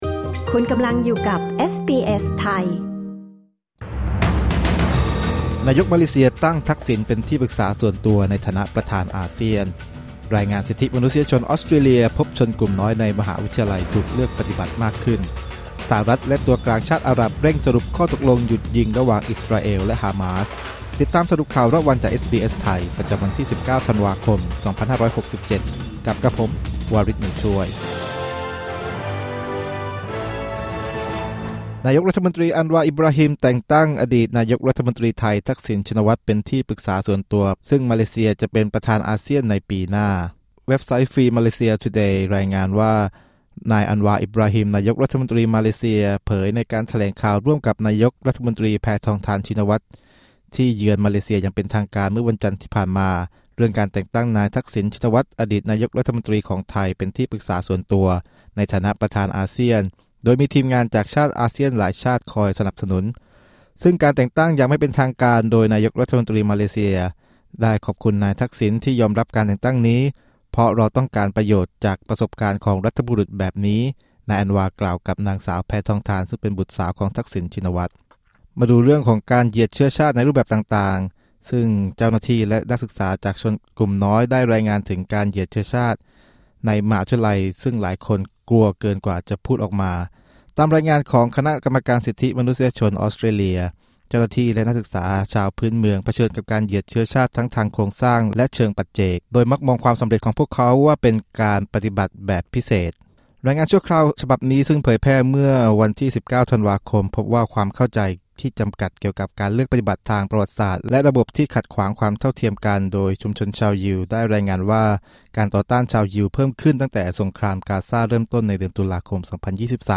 สรุปข่าวรอบวัน 19 ธันวาคม 2567